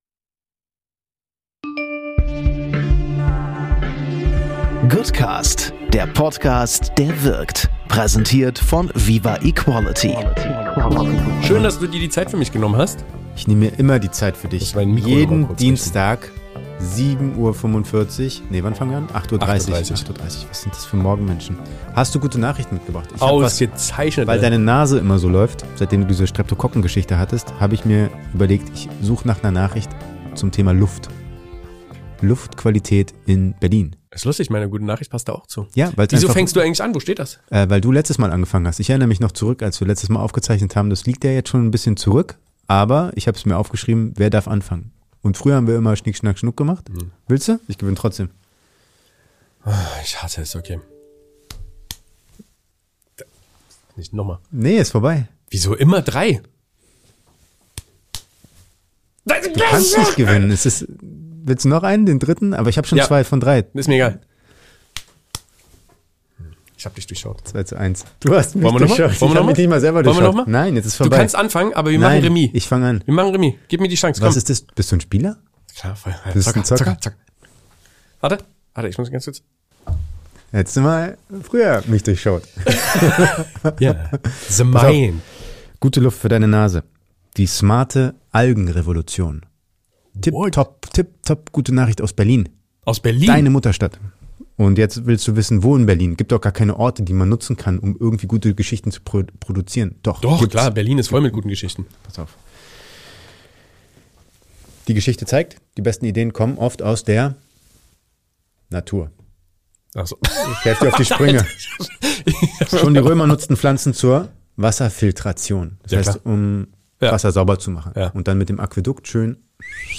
Zwischen flotten Sprüchen und non-toxischer Bromance fliegen einem wieder jede Menge gute Nachrichten um die Ohren. Von Algen bis Gender-Pay-Gap ist alles dabei.